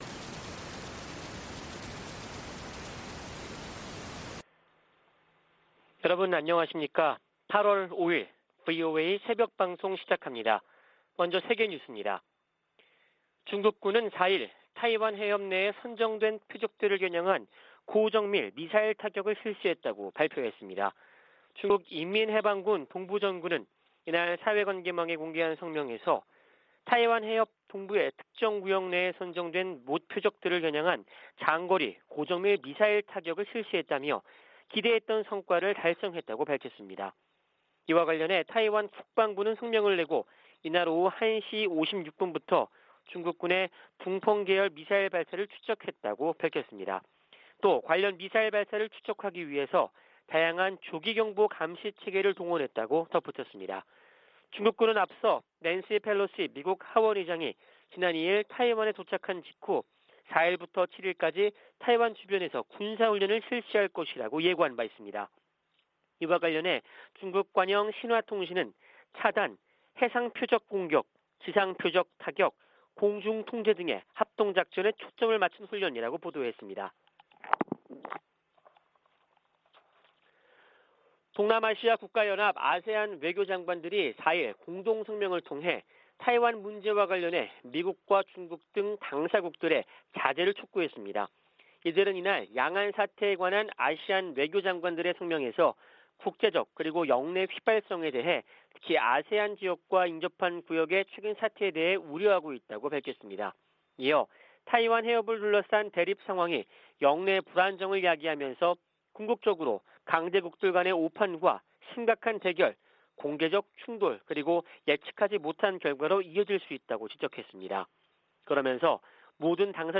VOA 한국어 '출발 뉴스 쇼', 2022년 8월 5일 방송입니다. 윤석열 한국 대통령이 낸시 펠로시 미 하원의장의 방한 행보에 관해 미한 대북 억지력의 징표라고 말했습니다. 미국은 한국에 대한 확장억제 공약에 매우 진지하며, 북한이 대화를 거부하고 있지만 비핵화 노력을 계속할 것이라고 국무부가 강조했습니다. 미국과 한국의 합참의장이 화상대화를 갖고 동맹과 군사협력 등에 관해 논의했습니다.